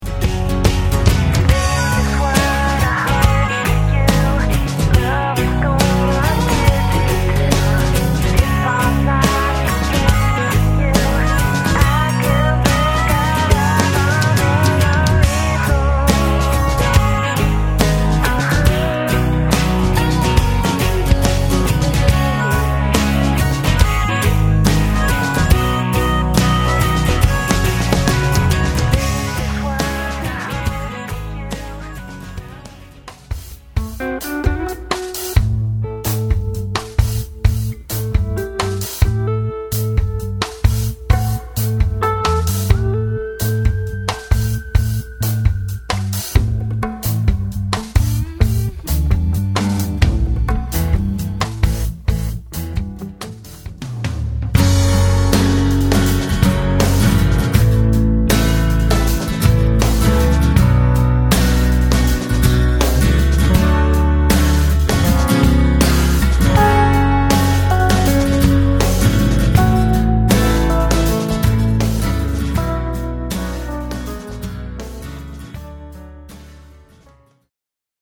Great drum performances with all Class A mics & preamps, great kit, pro recording studio
Pop Rock Funk Blues Country